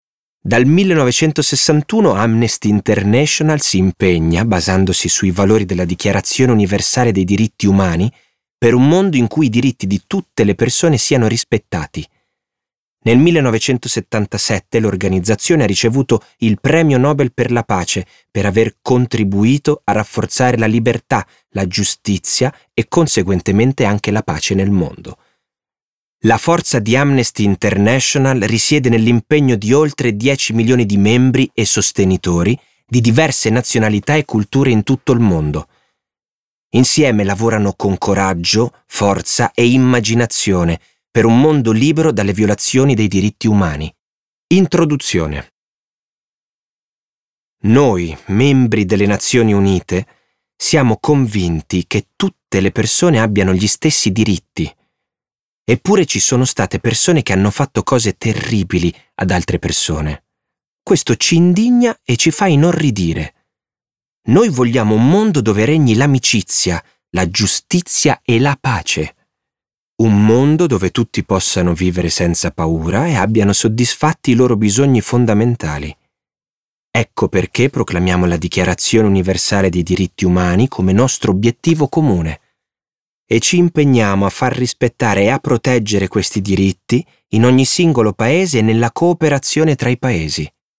letto da Pierpaolo Spollon
Versione audiolibro integrale